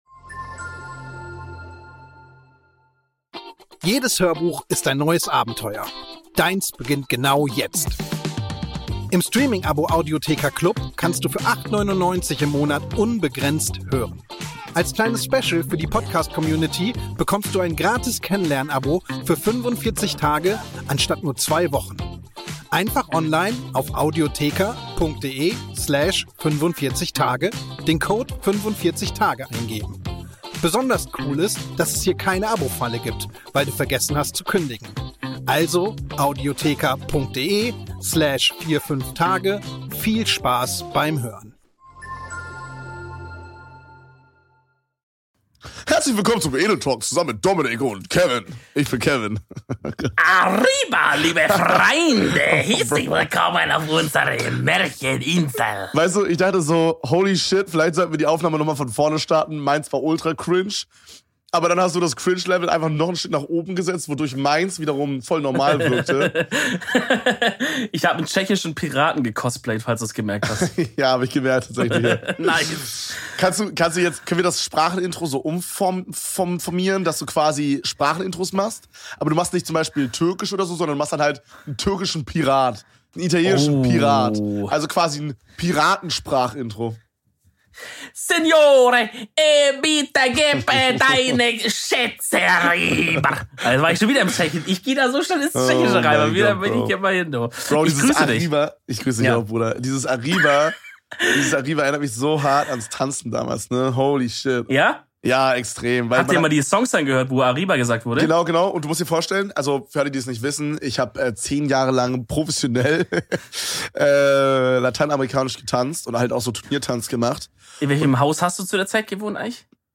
Das neue Sprachenintro wird heute präsentiert von einem tschechischen Piraten. Außerdem bekommt ihr eine ausführliche Einführung in das Thema Curry.